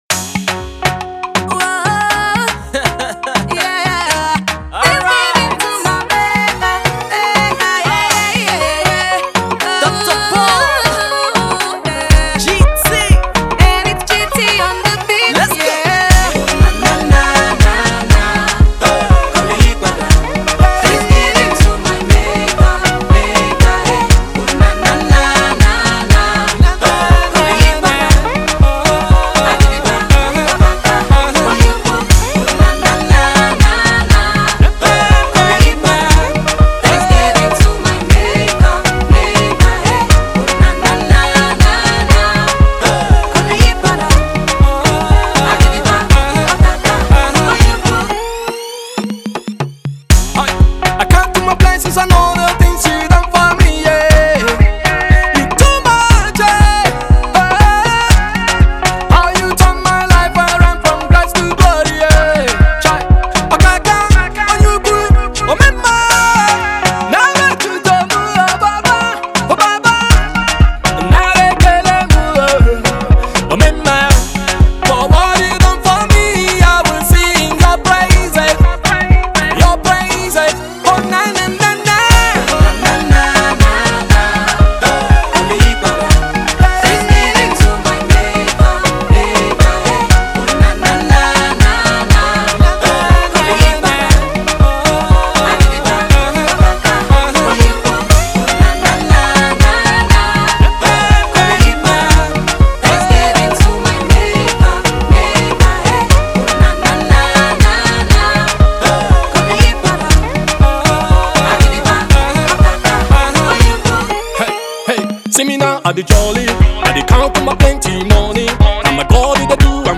hip hop praise song
is trendy, groovy, and powerful.